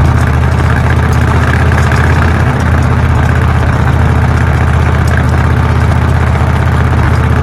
V8_engine.ogg